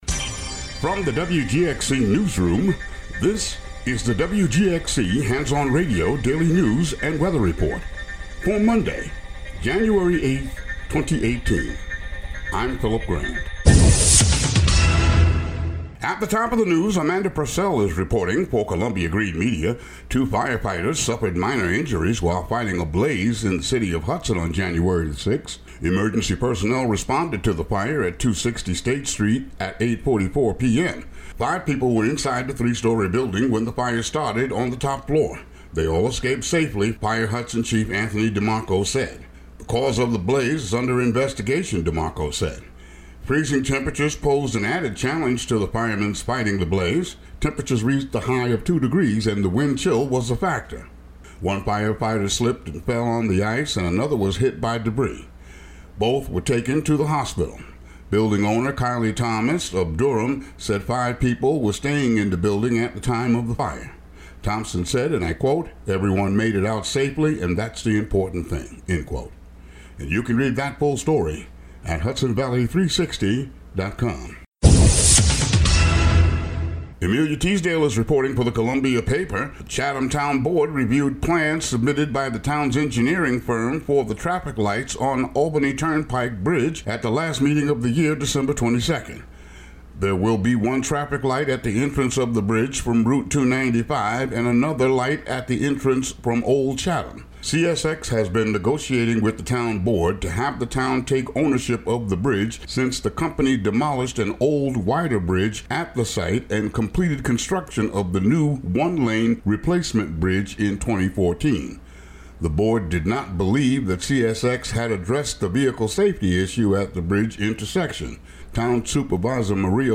Daily local news for Mon., Jan. 8.